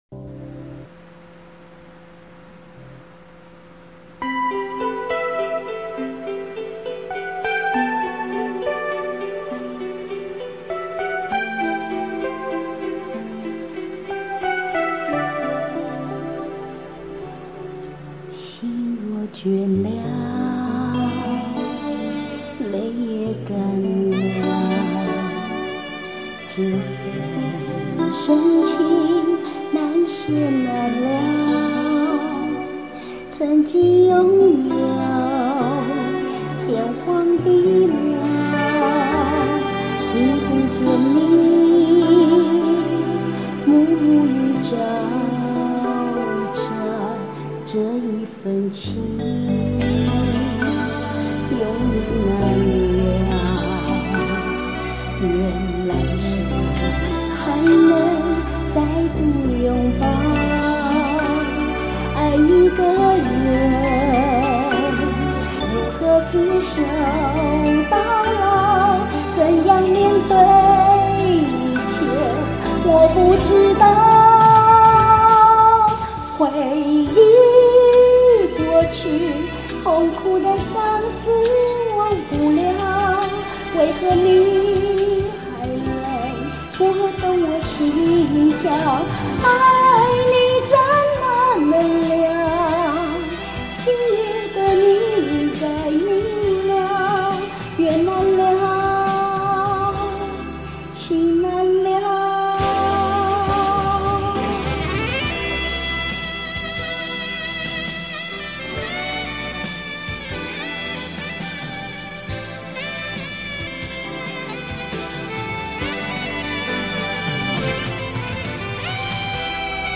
我錄音是使用手機錄音app
缺少爆發力的聲音啊..........